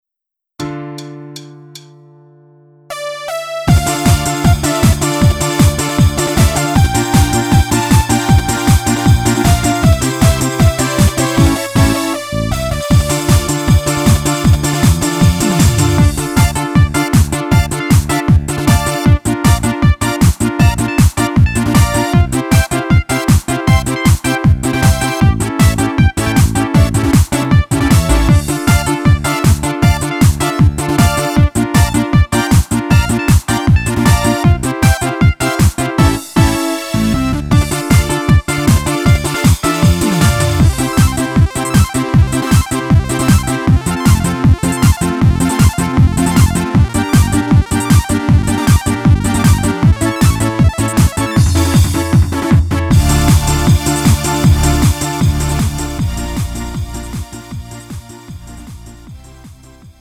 음정 원키 3:37
장르 구분 Lite MR